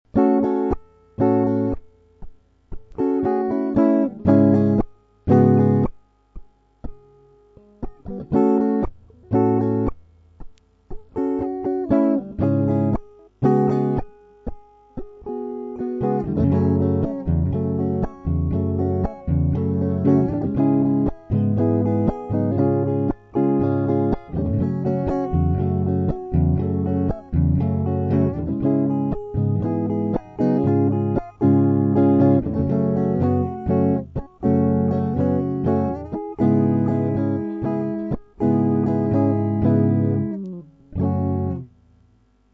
Проигрыш (F#m - C#m - E - C#):
mp3 - вступление и проигрыш